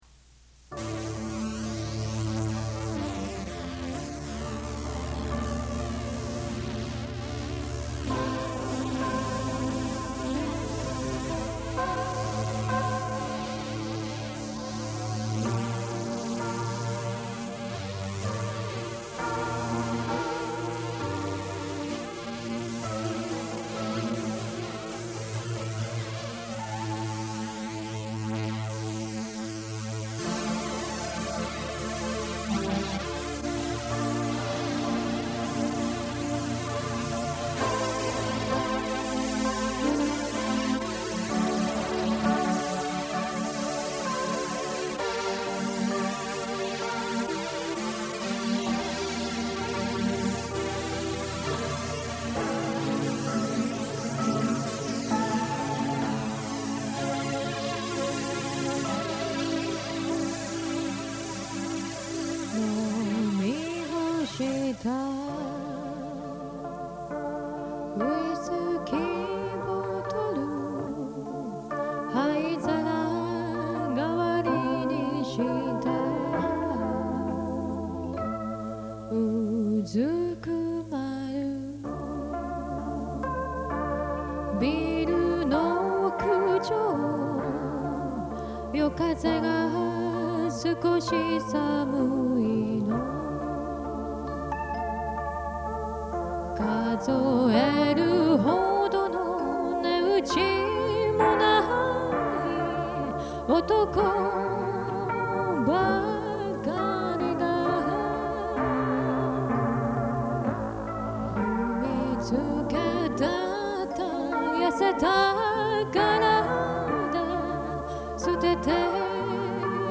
ここで紹介させていただきますのは，もう２０余年前，大学の頃，ひとり軽音楽部の部室で，多重録音で作ったオリジナル曲です。